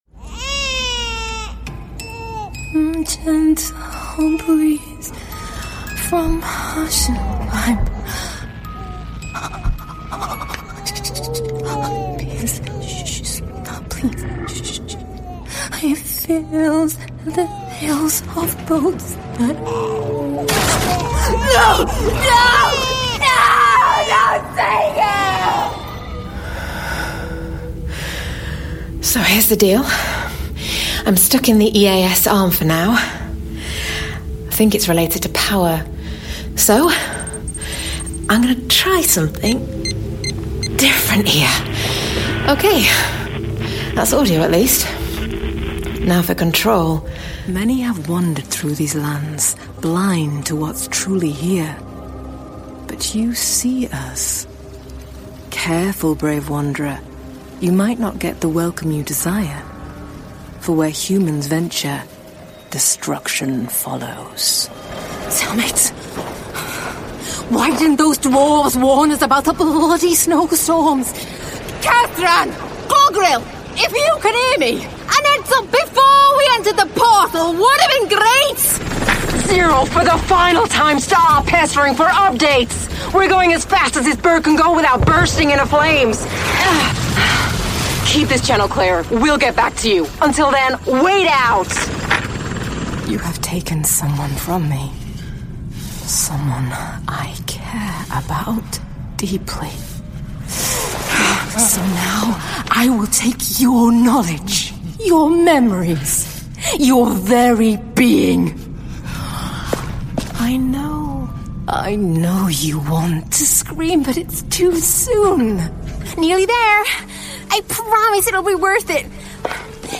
Natural, Versátil, Amable
She has a broadcast quality home studio and is a popular choice amongst clients.